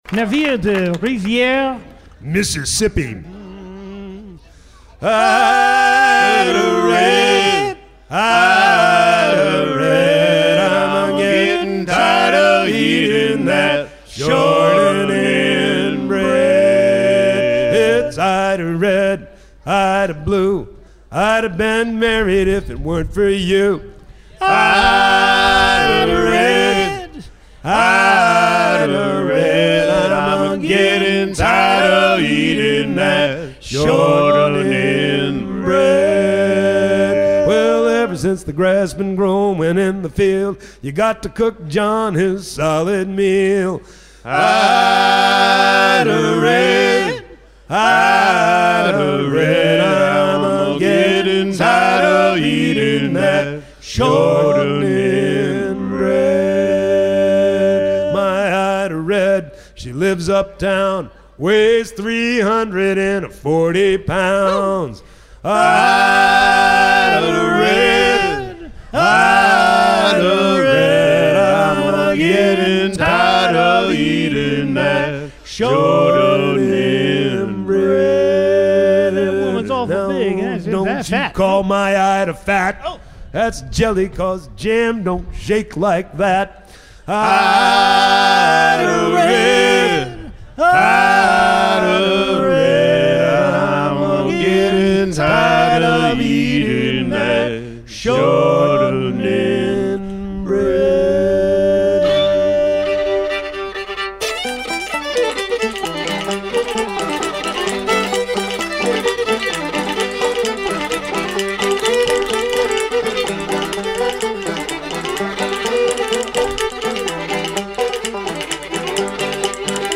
gestuel : à lever les filets
en concert
Pièce musicale éditée